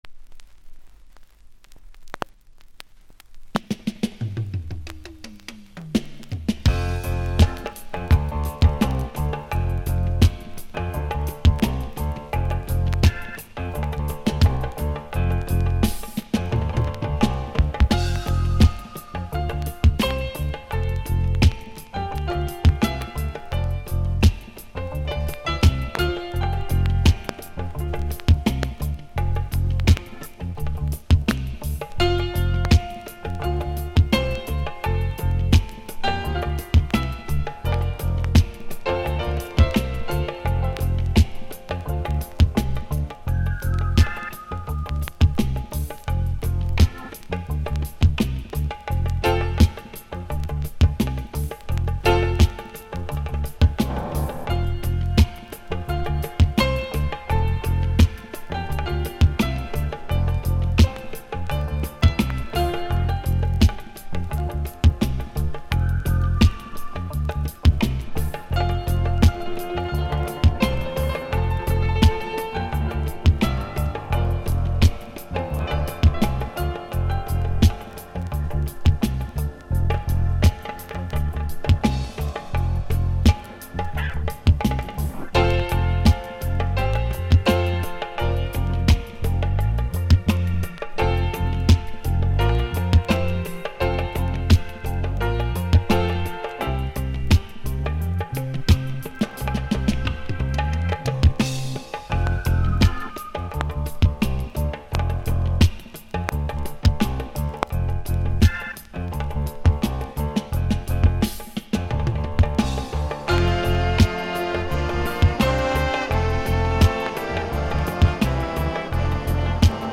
Genre Roots Rock / Inst
* ずっしりとしたルーツ・サウンドにエレガントなピアノ。
傷により所々パチノイズが目立ちます。フル尺で録音しているので、試聴にてご確認ください。